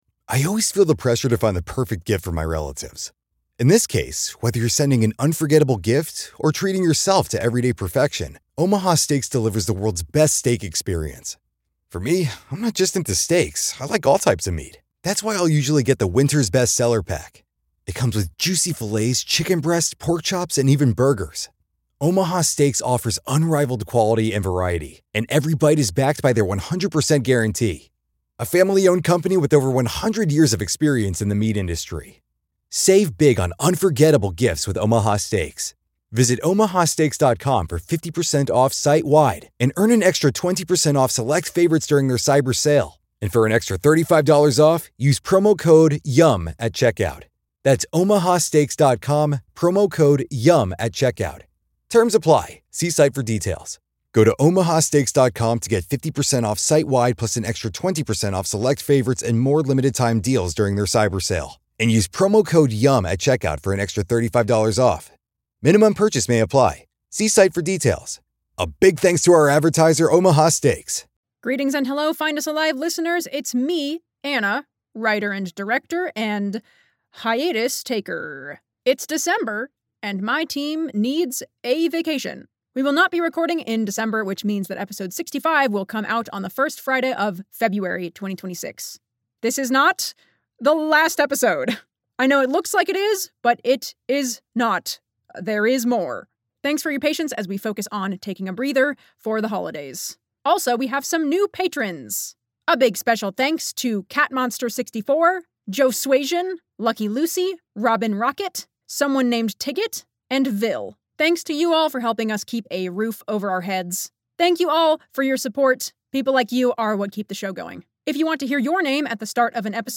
Find Us Alive follows the strange developments at Site-107, a small Foundation research site trapped inside dimensional anomaly SCP-6320, as narrated by the site’s radio operator, Dr. Harley.
… continue reading 70 epizódok # Audio Drama # Storytelling # Binge-Worthy Audio Drama # Binge-Worthy Fiction # Sci-Fi / Fantasy Stories # At Site107